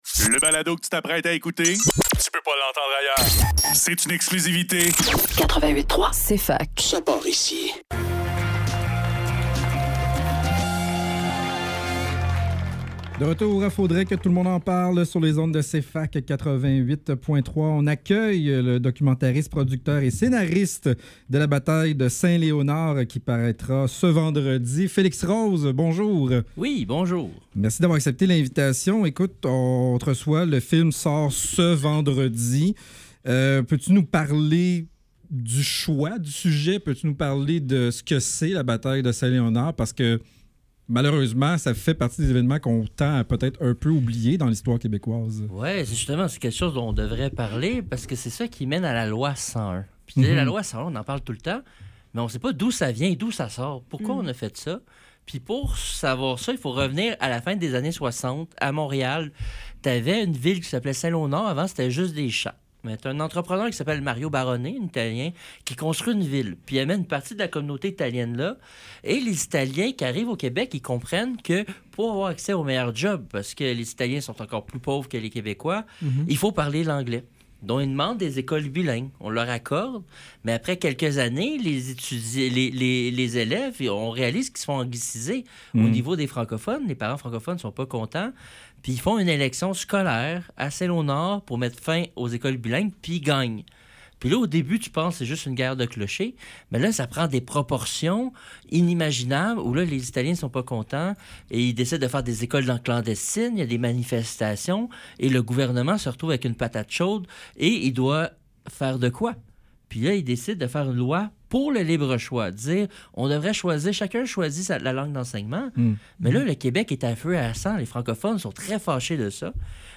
Faudrait que tout l'monde en parle - Entrevue